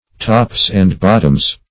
tops-and-bottoms - definition of tops-and-bottoms - synonyms, pronunciation, spelling from Free Dictionary
Search Result for " tops-and-bottoms" : The Collaborative International Dictionary of English v.0.48: Tops-and-bottoms \Tops"-and-bot`toms\, n. pl.